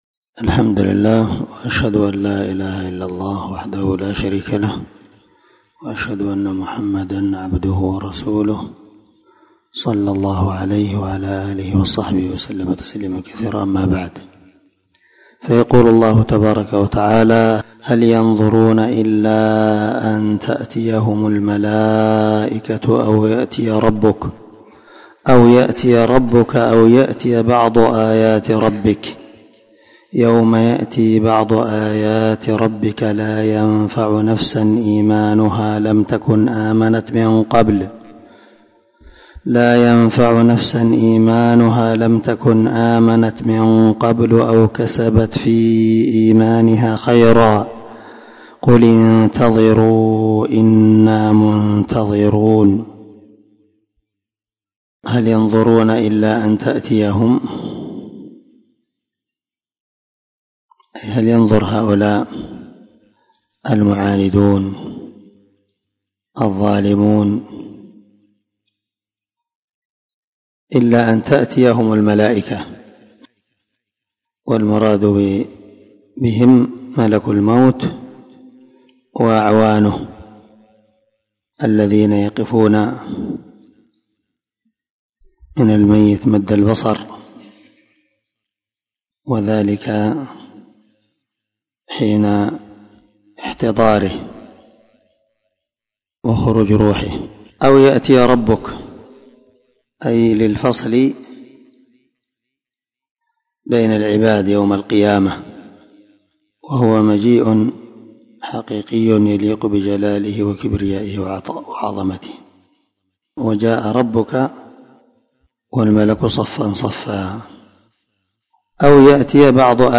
447الدرس 55 تفسير آية ( 158 - 160 ) من سورة الأنعام من تفسير القران الكريم مع قراءة لتفسير السعدي